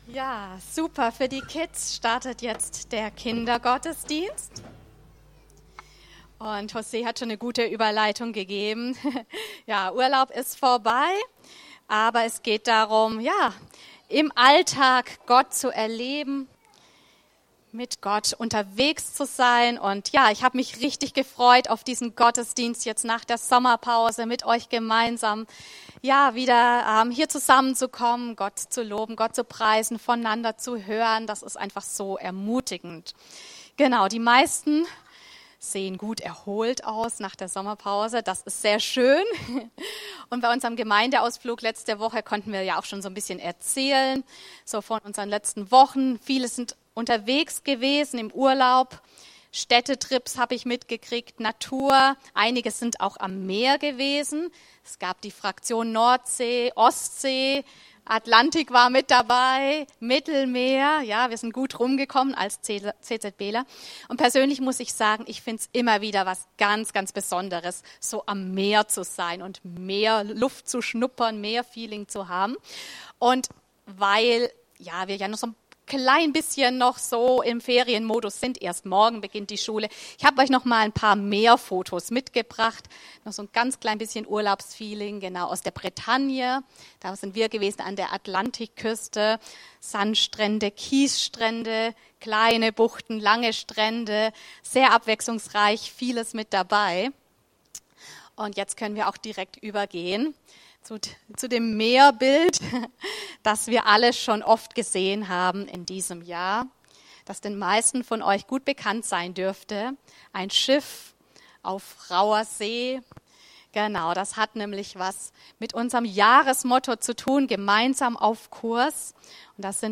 Den Glauben weitergeben ~ CZB Bensheim Predigten Podcast
Aktuelle Predigten aus unseren Gottesdiensten und Veranstaltungen